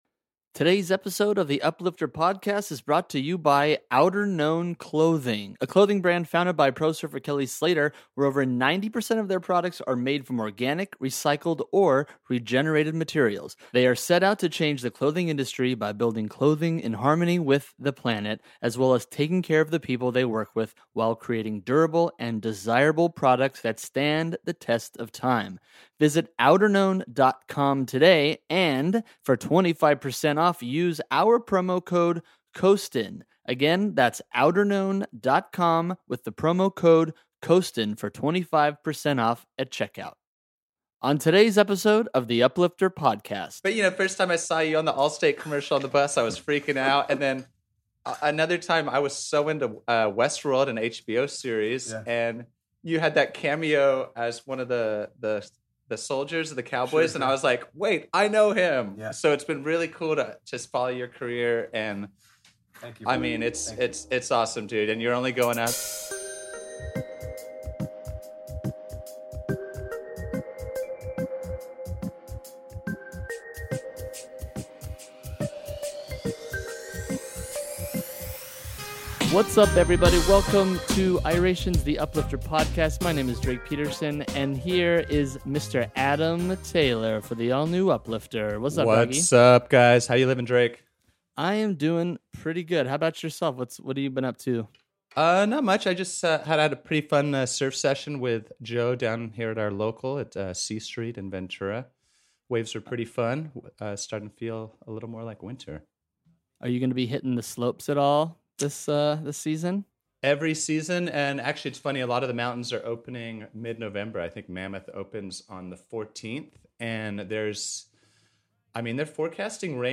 One member of the band Iration interviews a surprise guest of their choice to offer an insight into the lives of other humans through the shared bond of music.
We hope you enjoy this exclusive interview where he shares some breaking news about upcoming projects and talks about the struggles he has overcome during his exciting Hollywood career.